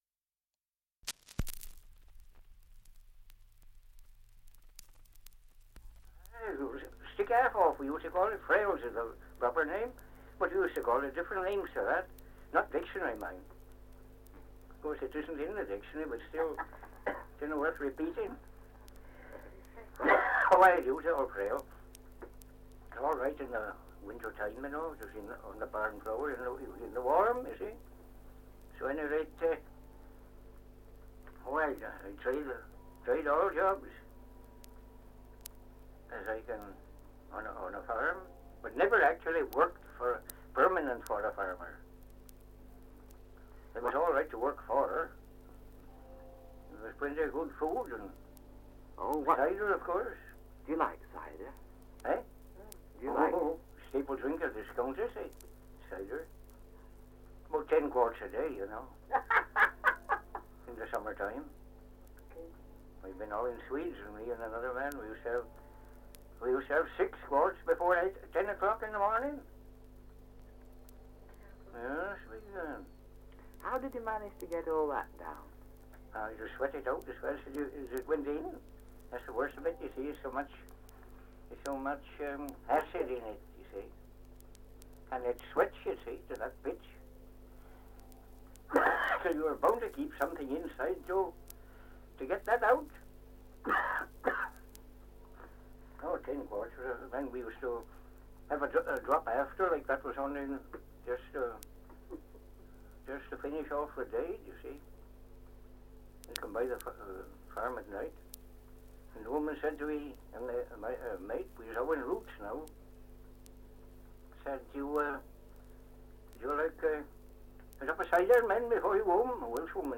Survey of English Dialects recording in Lyonshall, Herefordshire
78 r.p.m., cellulose nitrate on aluminium